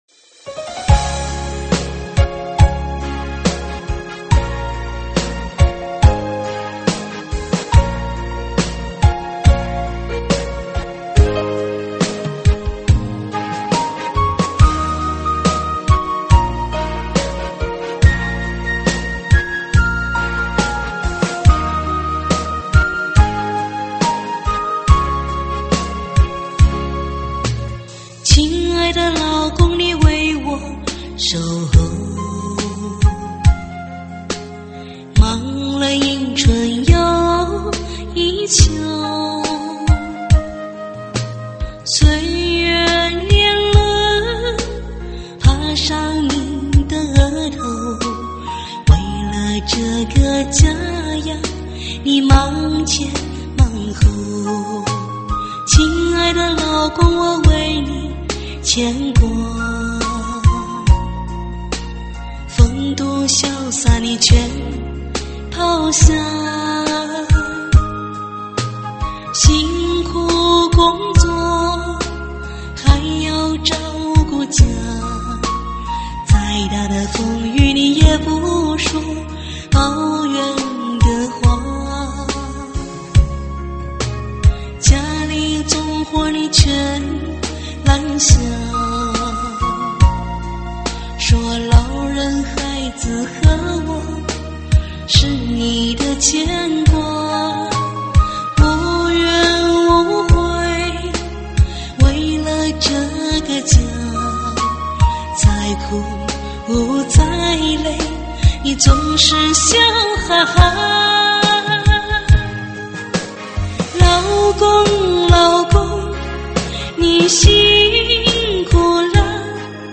（抒情篇第二辑）老公老公辛苦了车载慢四节奏CD碟.mp3